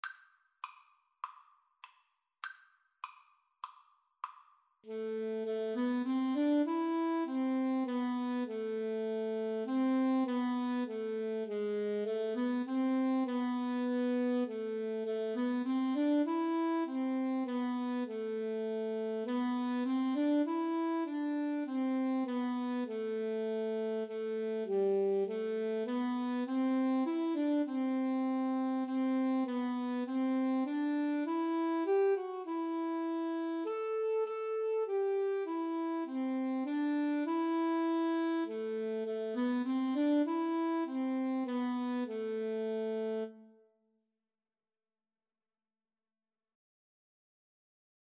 Classical Parry, Hubert Aberystwyth Alto Sax-Guitar Duet version
4/4 (View more 4/4 Music)
A minor (Sounding Pitch) (View more A minor Music for Alto Sax-Guitar Duet )
Moderato
Classical (View more Classical Alto Sax-Guitar Duet Music)